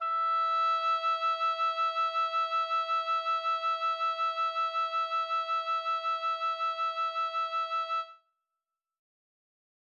Haz click en la nota que desees para escuchar el sonido que debería producir cada cuerda: